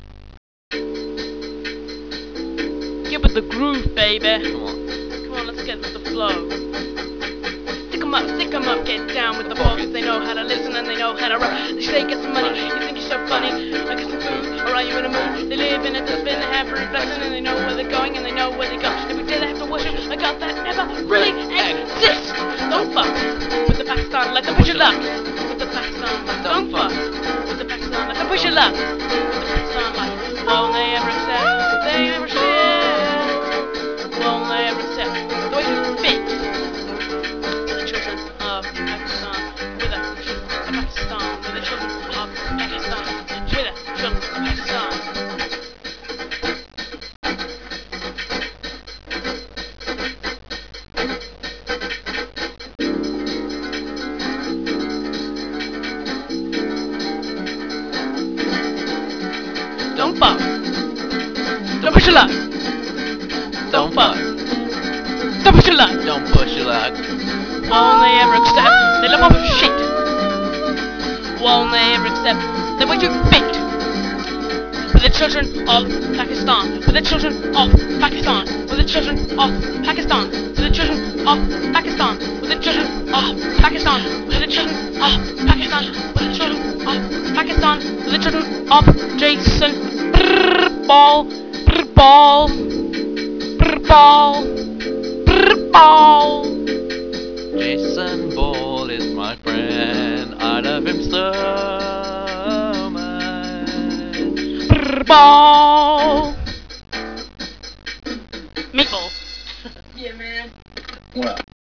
Child [acoustic]